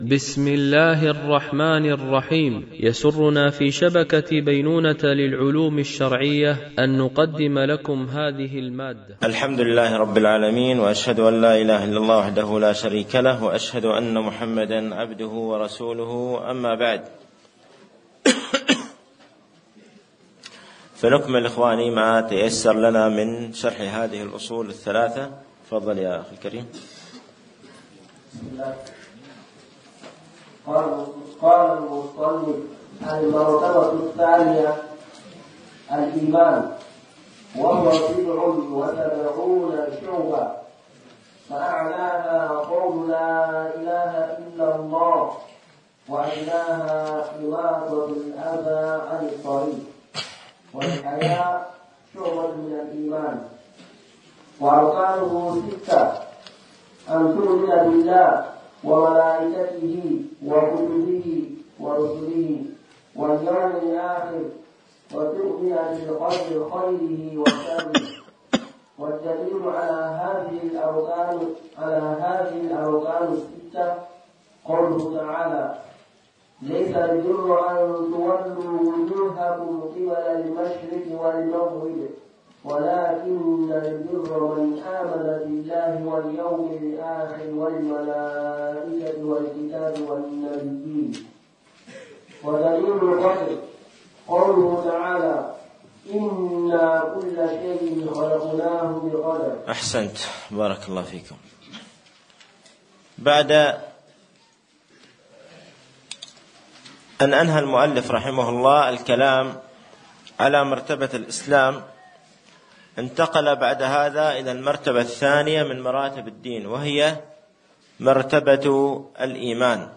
شرح الأصول الثلاثة ـ الدرس 09